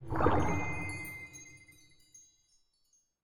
Minecraft Version Minecraft Version snapshot Latest Release | Latest Snapshot snapshot / assets / minecraft / sounds / mob / glow_squid / ambient2.ogg Compare With Compare With Latest Release | Latest Snapshot
ambient2.ogg